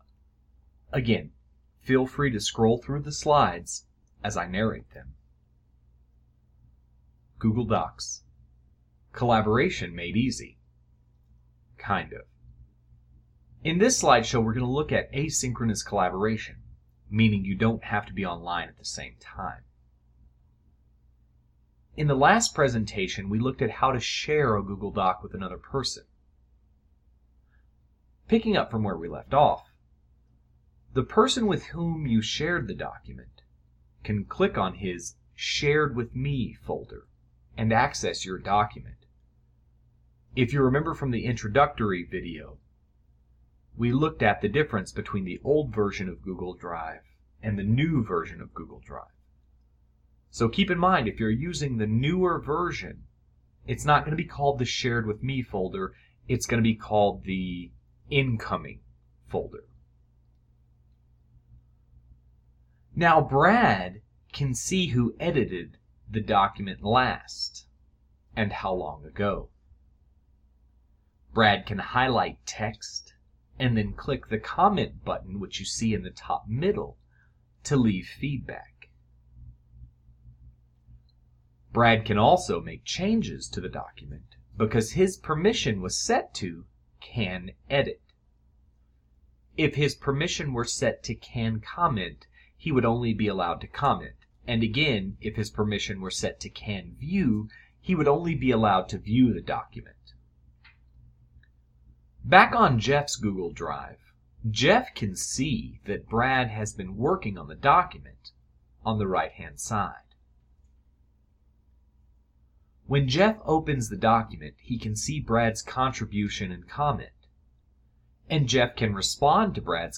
Scroll through the slides as I narrate them.